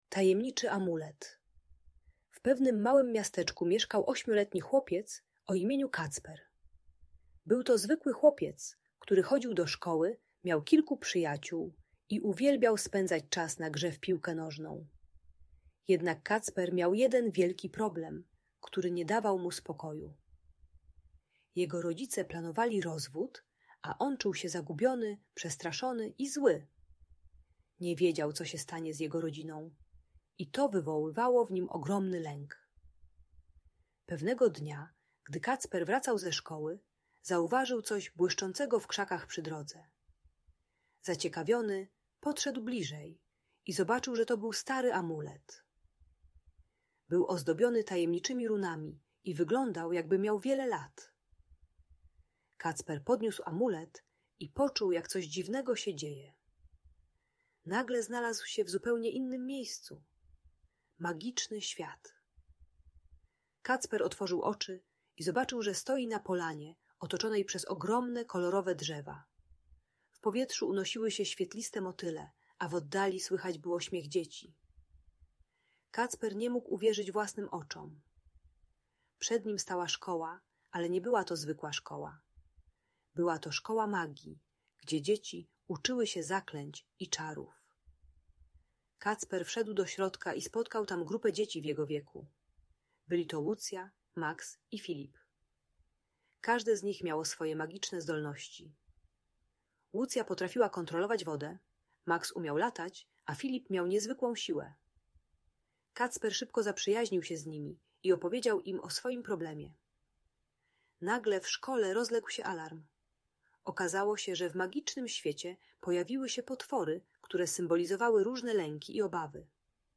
Tajemniczy Amulet - Magiczna Historia o Odwadze - Audiobajka